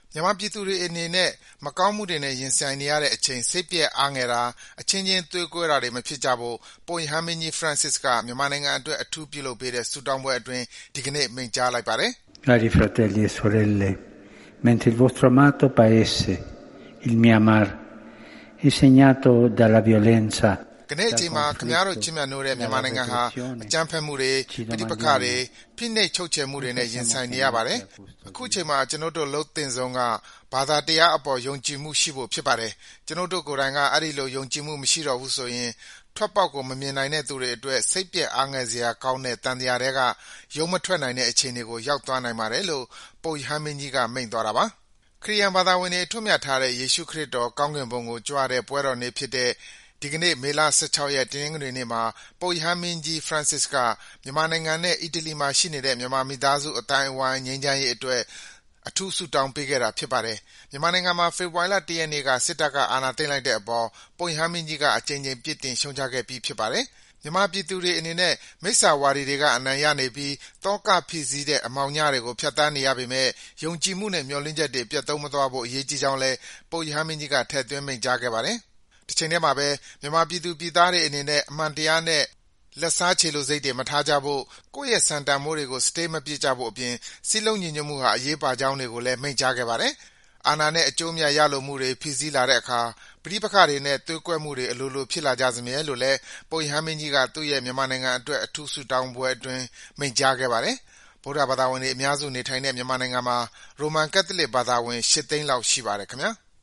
မြန်မာပြည်သူတွေအနေနဲ့ မကောင်းမှုတွေနဲ့ ရင်ဆိုင်နေရတဲ့အချိန် စိတ်ပျက် အားငယ်တာ၊ အချင်းချင်း သွေးကွဲတာတွေ မဖြစ်ကြဖို့ ပုပ်ရဟန်းမင်းကြီး ဖရန်စစ်က မြန်မာနိုင်ငံအတွက် အထူးပြုလုပ်ပေးတဲ့ ဆုတောင်းပွဲအတွင်း ဒီကနေ့ မိန့်ကြားလိုက်ပါတယ်။